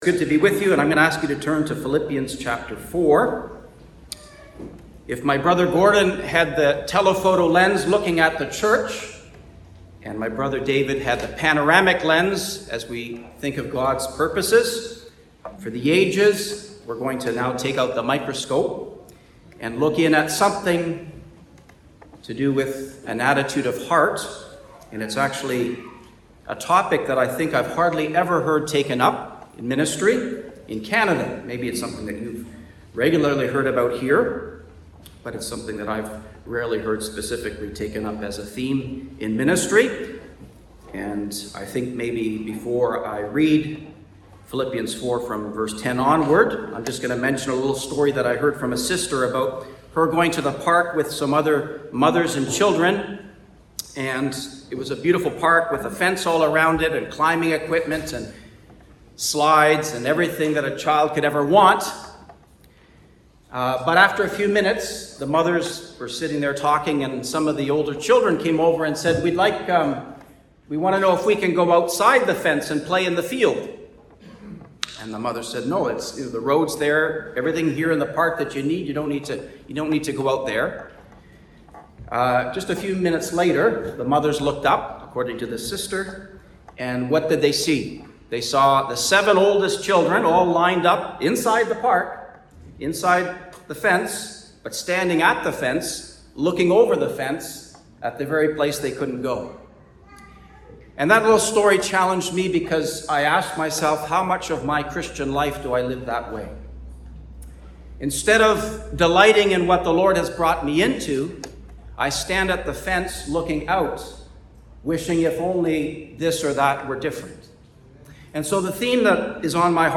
Location: Cooroy Gospel Hall Conference 2025 (Cooroy, QLD, Australia)